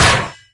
CosmicRageSounds / ogg / general / combat / armor / 5.ogg